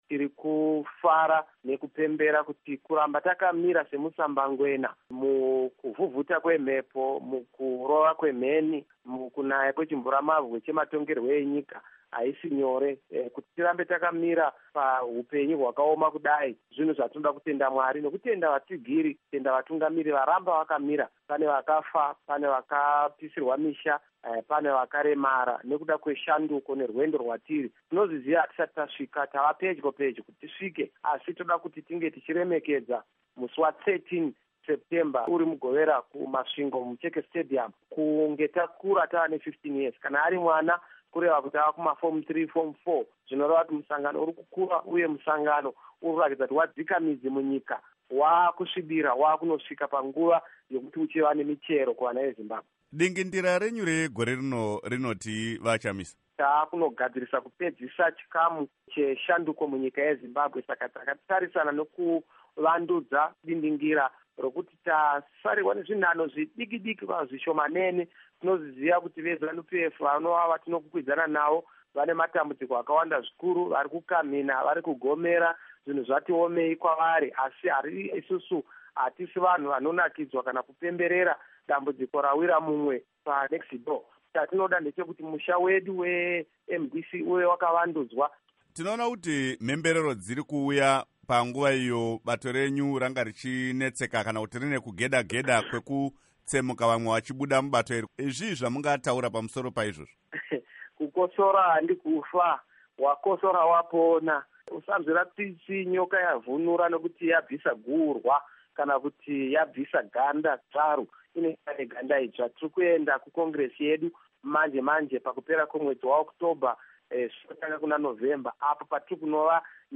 Hurukuro naVaNelson Chamisa